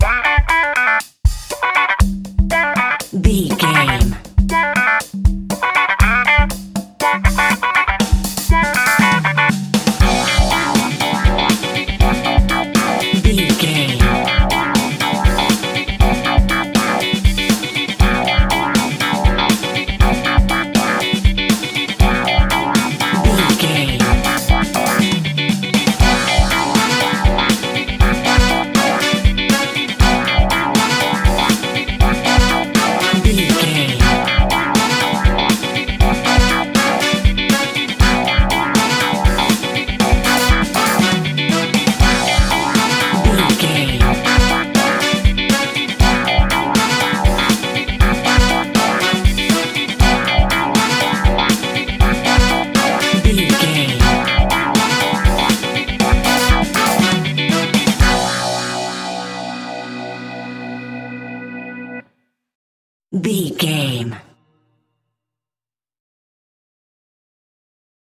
Aeolian/Minor
groovy
uplifting
bouncy
drums
electric guitar
percussion
bass guitar
horns
funky house
disco house
electronic funk
upbeat
synth leads
Synth pads
synth bass
drum machines